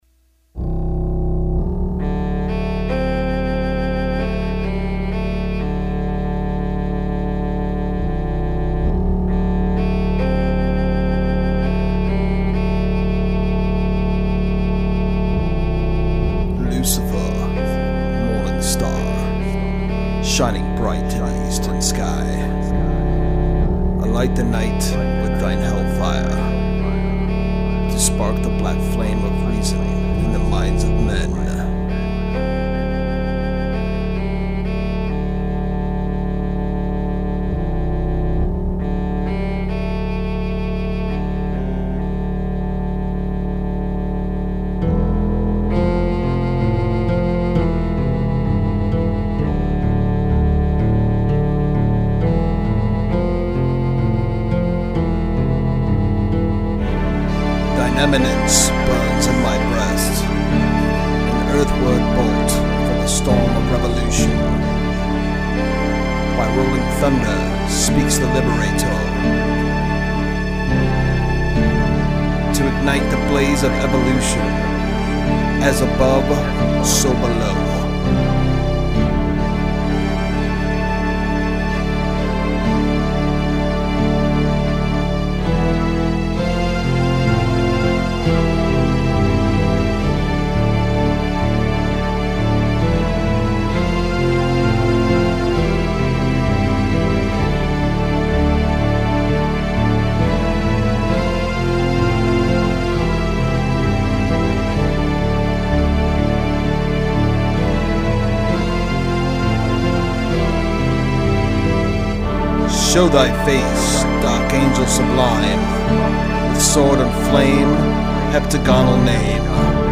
| Instrumental From Los Angeles, CA